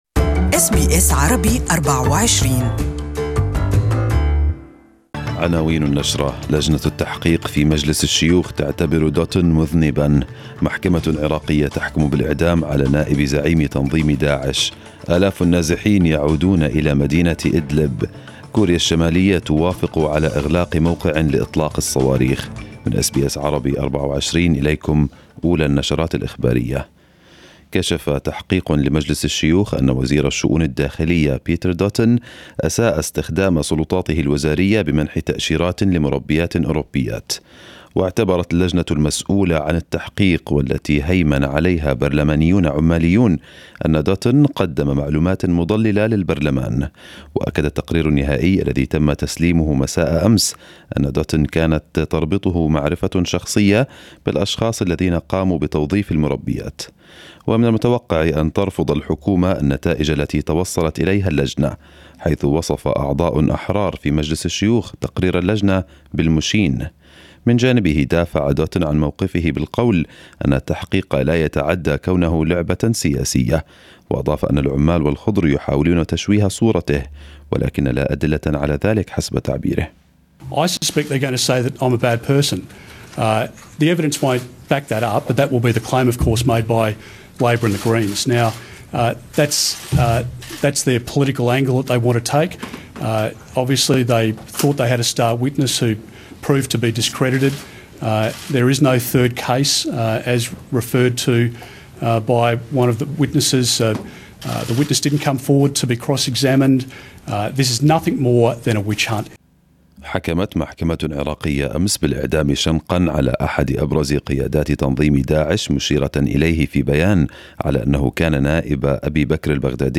First new bulletin this morning in Arabic